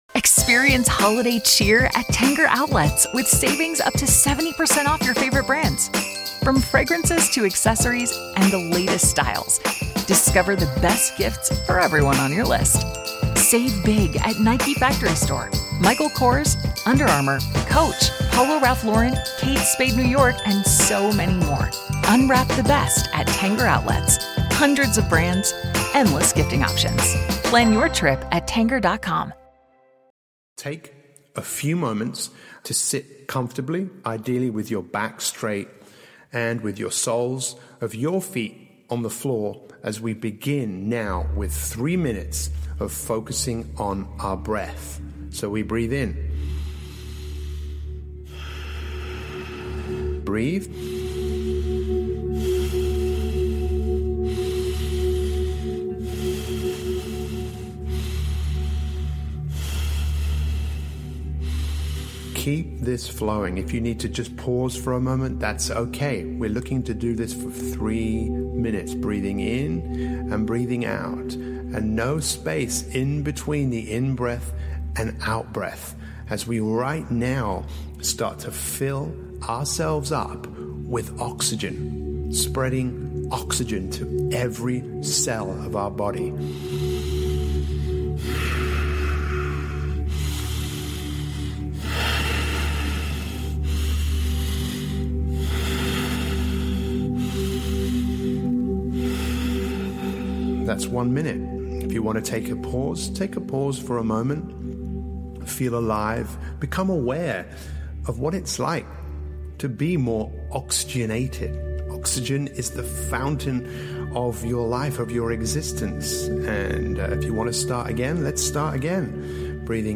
The Power of Adaptation - Powerful Motivational Speech.